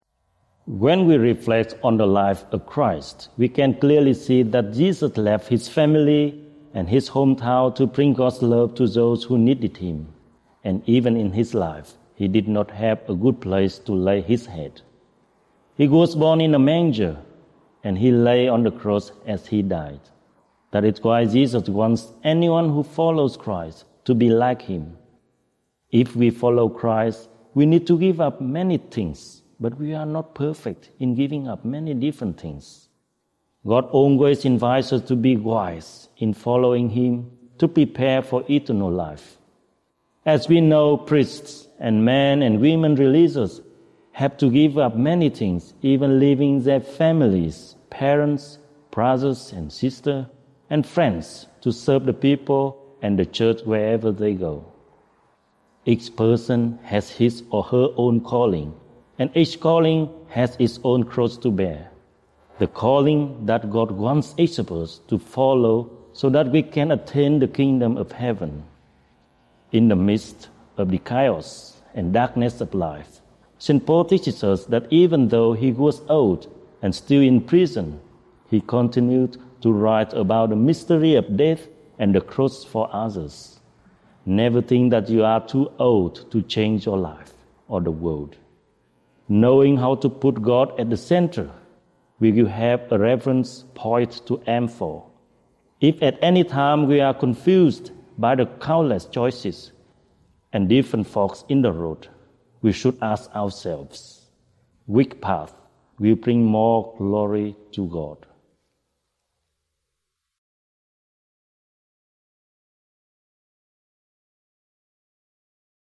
Archdiocese of Brisbane Twenty-Third Sunday in Ordinary Time - Two-Minute Homily